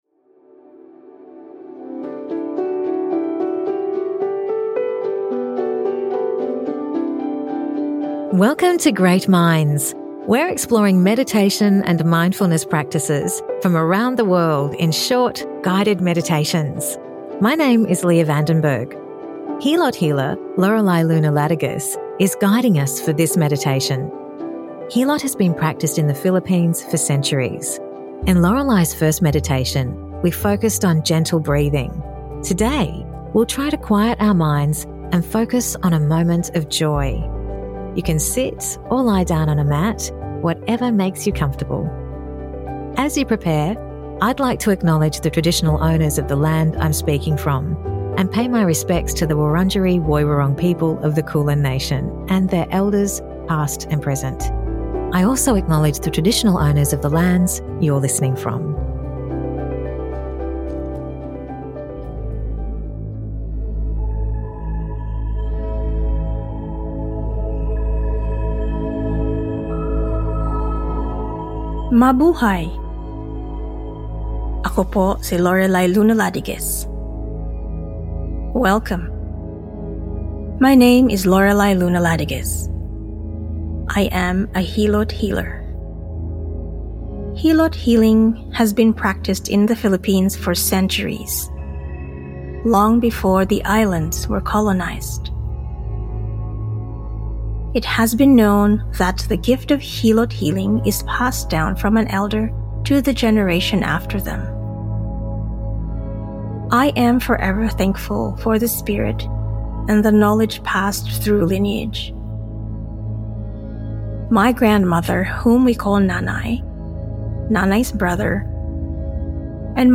Hilot meditation 2: Mind